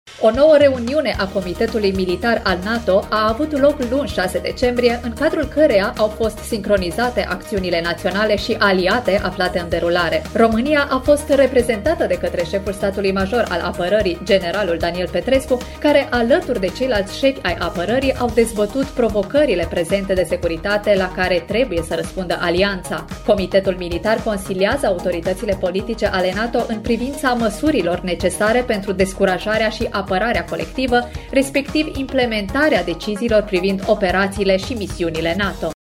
O-noua-reuniune-a-Comitetului-Militar-al-NATO-stire-1.mp3